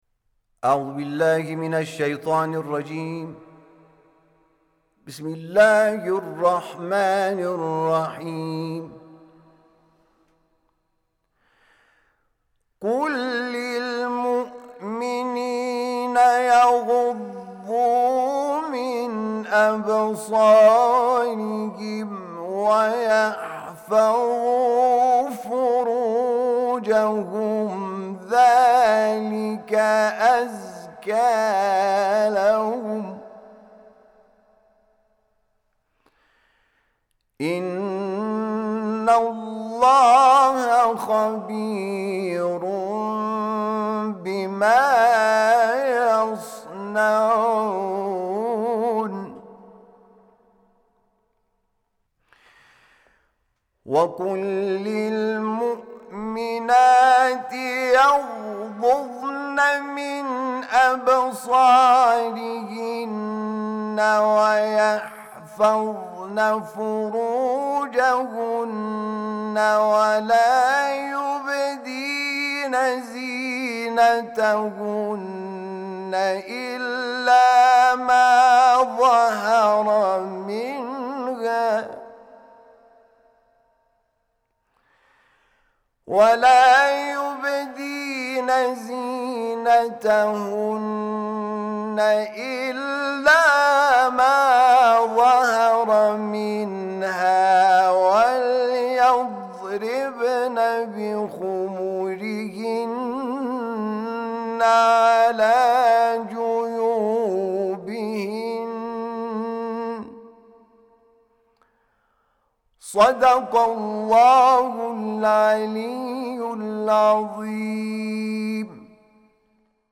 تلاوت آیه ۳۰ و ۳۱ سوره مبارکه‌ نور توسط حامد شاکرنژاد